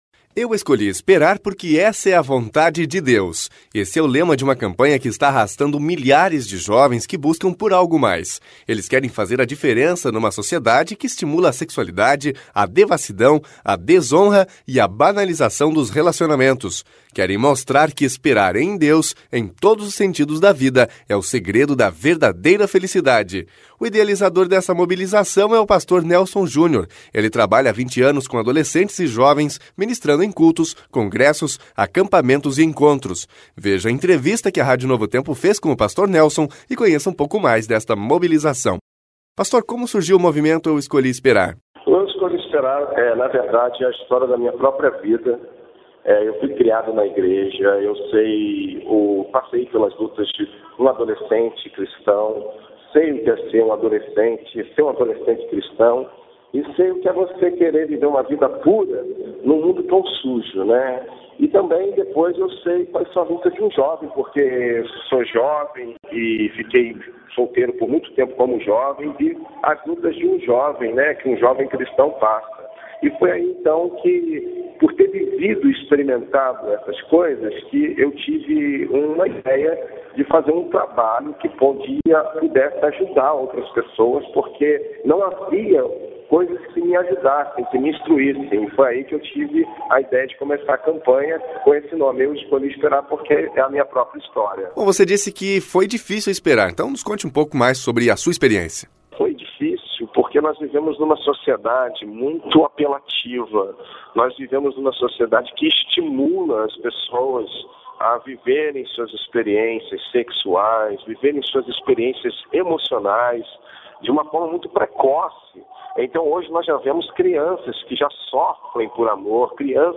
Ouça a entrevista completa: Podcast: Download (8.0MB) Rádio Novo Tempo – Como surgiu o movimento “Eu escolhi esperar”?